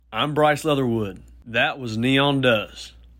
LINER Bryce Leatherwood (Neon Does) 4
LINER-Bryce-Leatherwood-Neon-Does-4.mp3